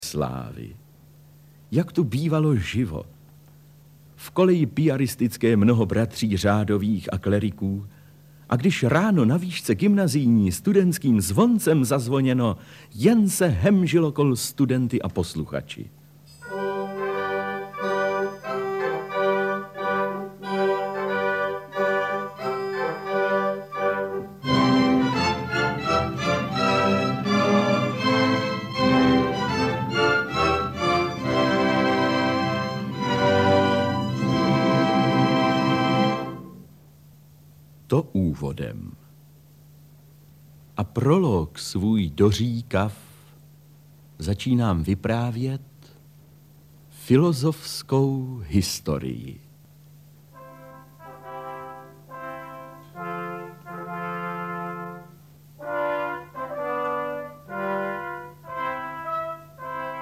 Audiobook
Read: Otakar Brousek